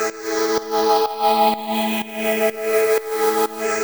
Index of /musicradar/sidechained-samples/125bpm
GnS_Pad-alesis1:4_125-A.wav